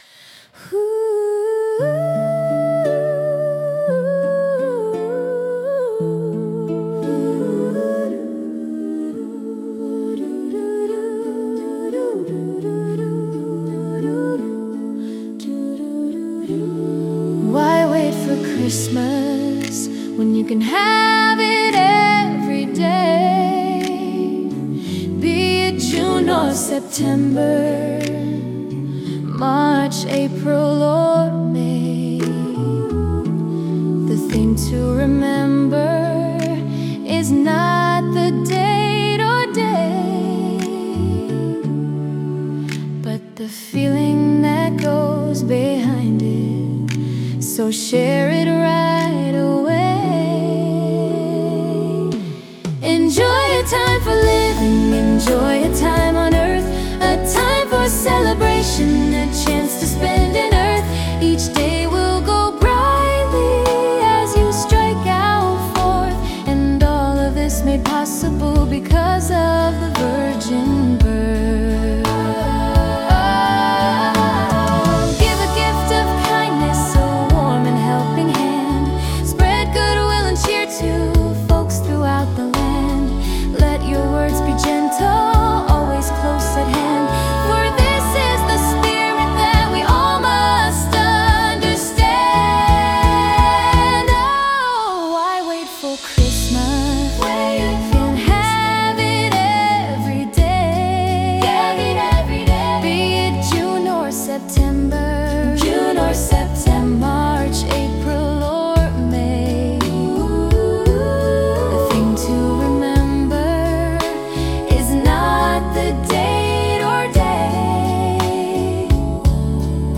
Posted by on November 5, 2025 in a new Christmas Carol, Christmas songs, Christmas story, new christmas song